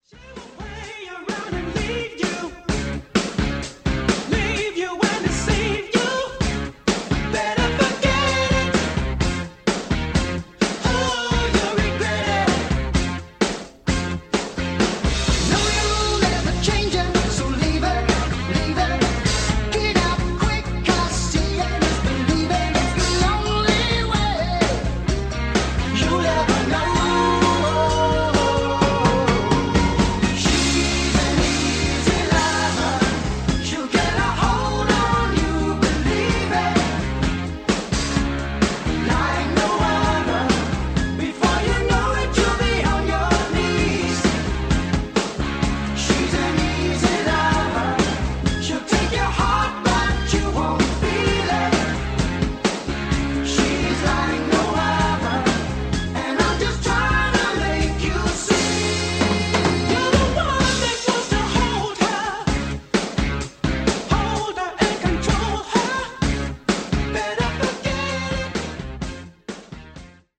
Below is a test recording made with the KD-A2 and played back by it:
Type: 2-head, single compact cassette deck
JVC-KD-A1-Test-Recording.mp3